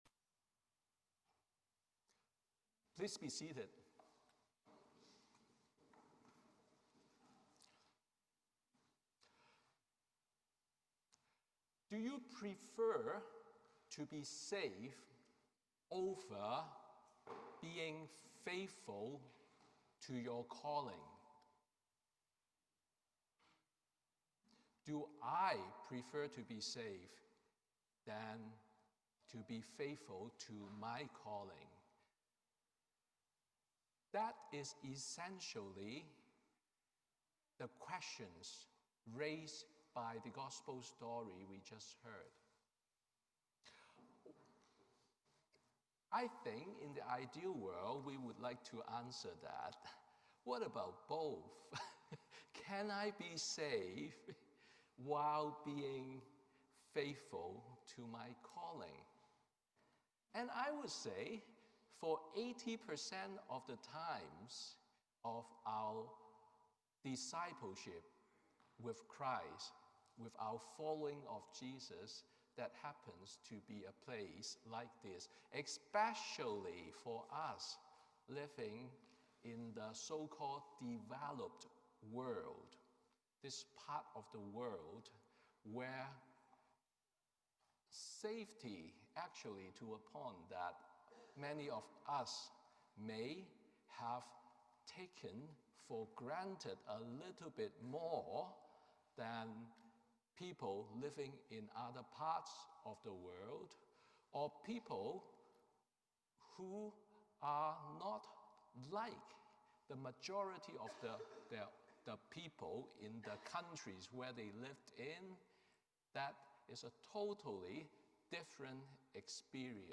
Sermon on the Second Sunday in Lent